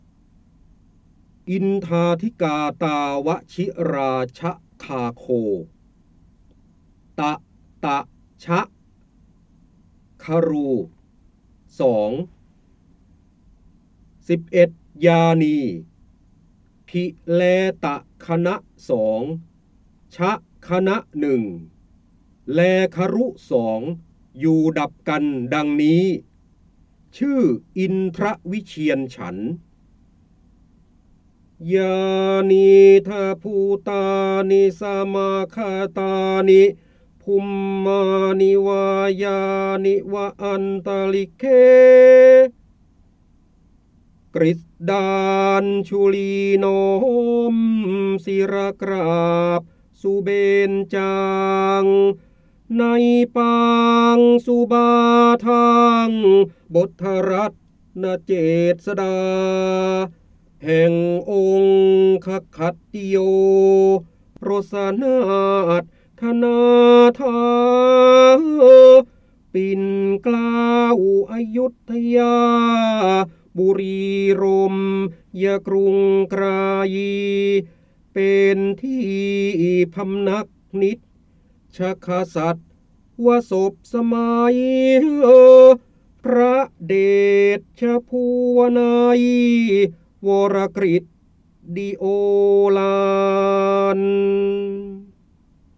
เสียงบรรยายจากหนังสือ จินดามณี (พระโหราธิบดี) อินทาทิกาตาวชิราชคาโค
คำสำคัญ : พระโหราธิบดี, ร้อยกรอง, การอ่านออกเสียง, จินดามณี, ร้อยแก้ว, พระเจ้าบรมโกศ
ลักษณะของสื่อ :   คลิปเสียง, คลิปการเรียนรู้